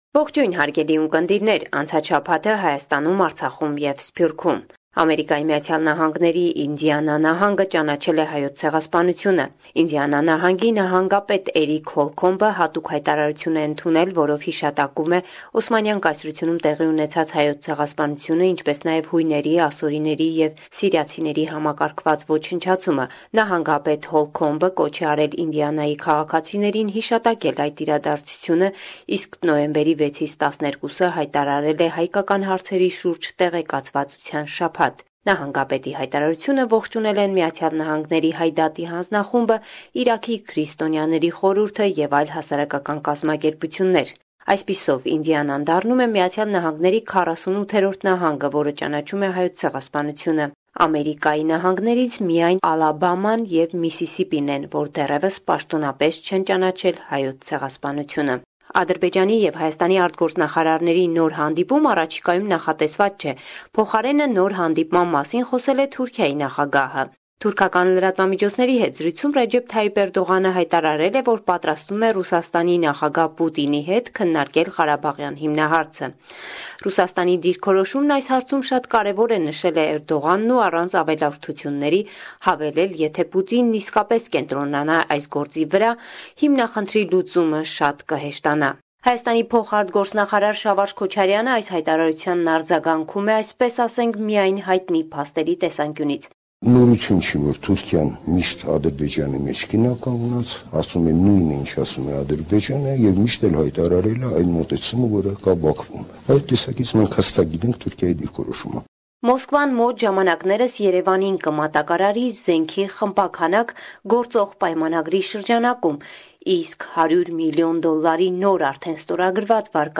Վերջին Լուրերը – 7 Նոյեմբեր, 2017